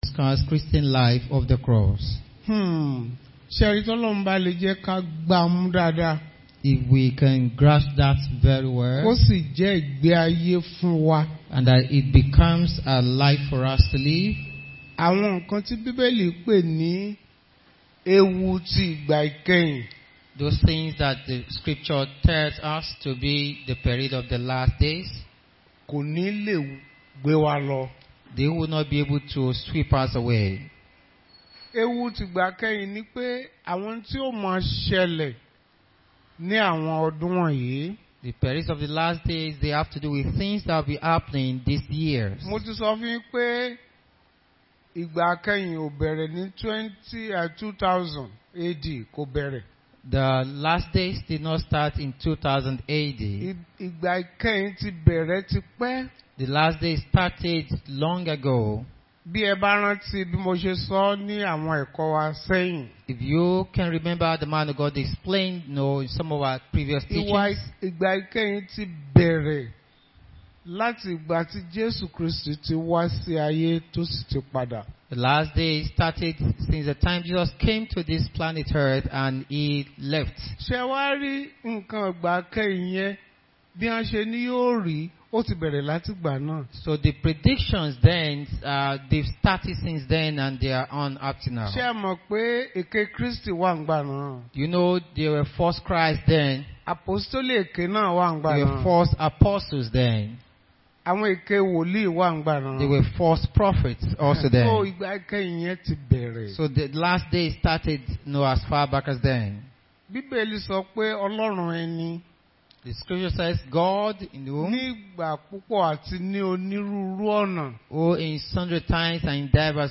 Bible Class Passage: Genesis 3:4-6, 2 Corinthians 11:1-15, Galatians 1:6-10